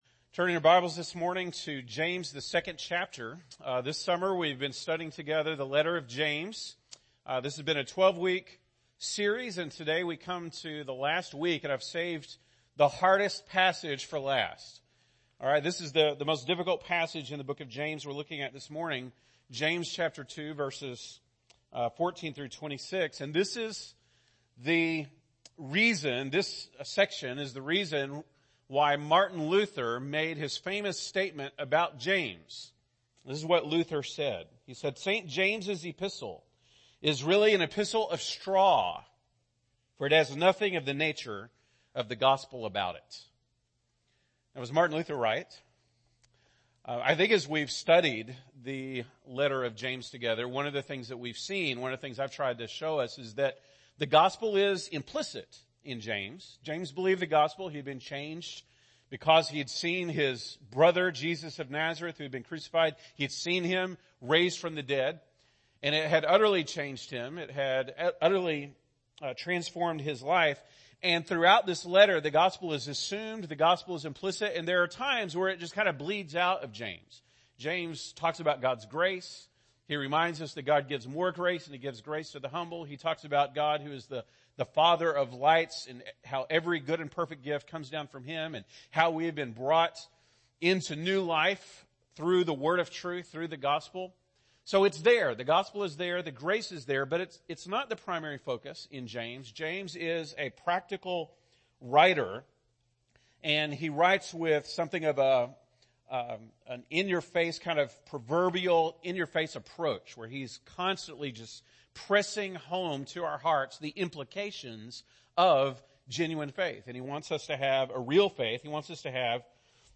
August 12, 2018 (Sunday Morning)